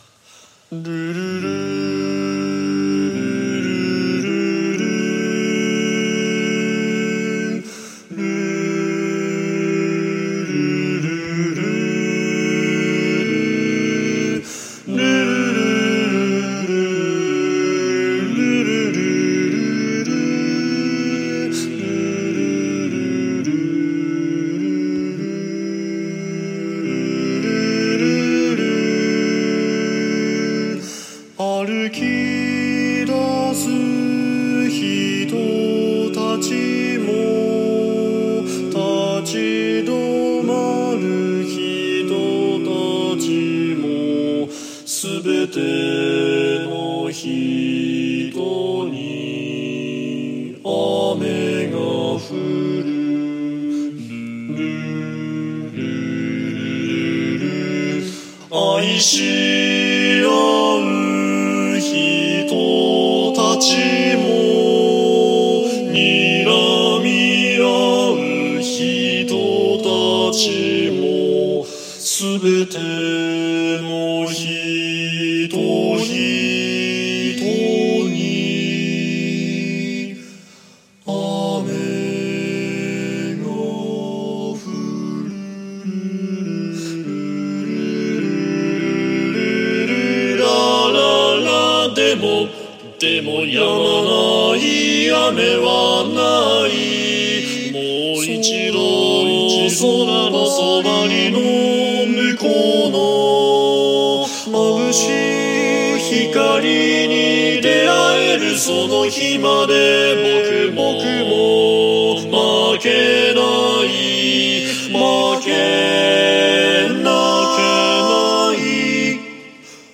無伴奏男声合唱曲「雨上がりの空に」
No Top Part
No Part：当該パートの音量をゼロにしています。
aftertherain_no_top.mp3